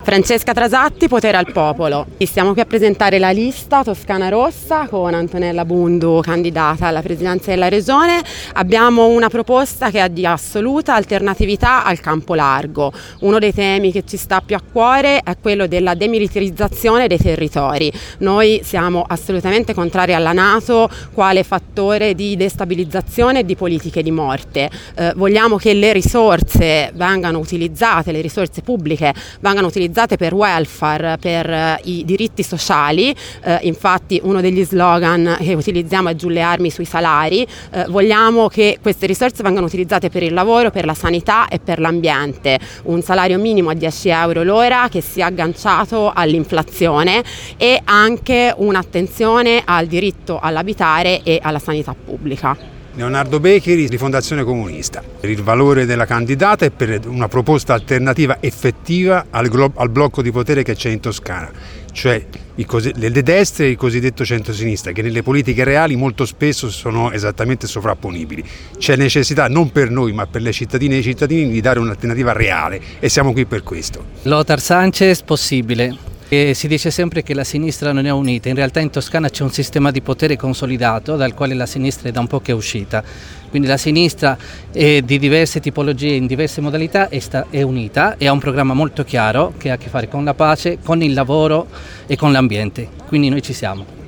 ASCOLTA L’INTERVENTO DELLE FORZE CHE COMPONGONO LA LISTA